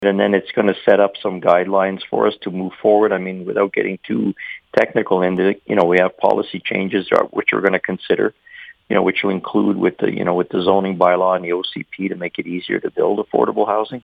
Mayor Frank Konrad says the survey is a good first step in hearing the community’s concerns.